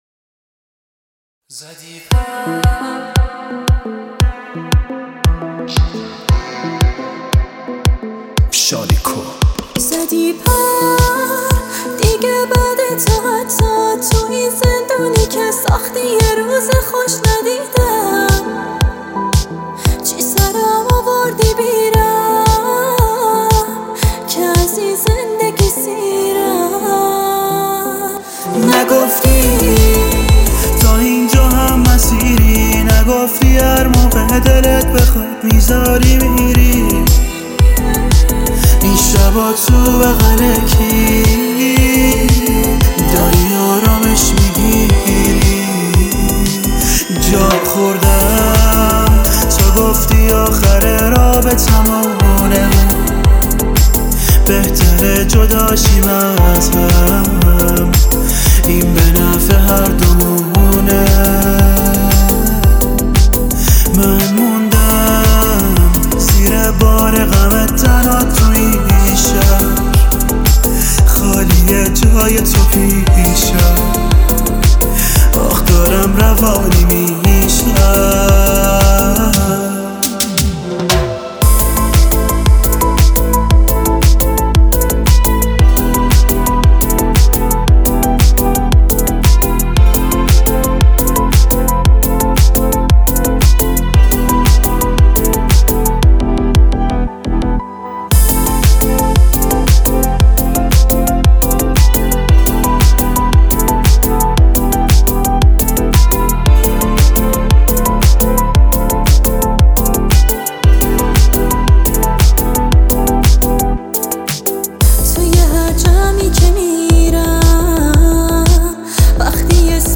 Listen And Download Pop Music
pop style
Enter your comments about this emotional music for us.